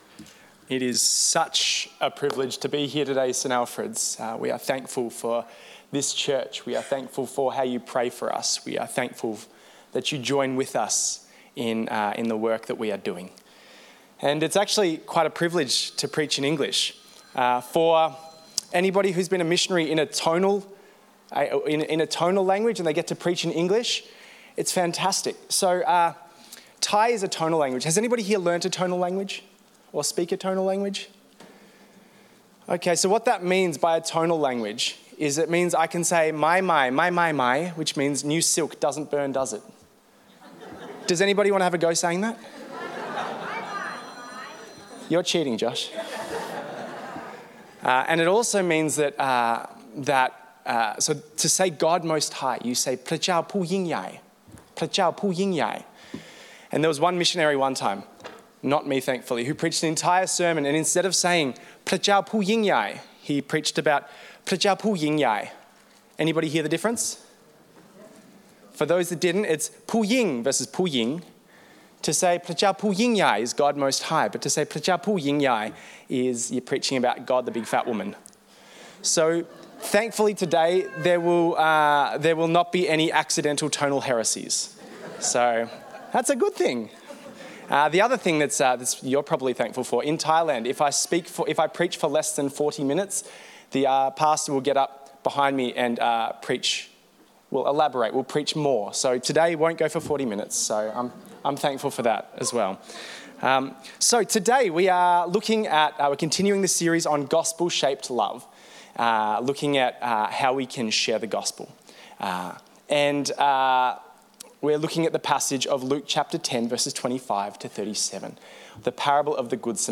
In this sermon, Guest Preacher speaks on the theme of 'Gospel-Shaped Love' as part of the series 'Gospel Shaped Life and Witness According to Luke'. The Bible reading is Luke 10:25–37.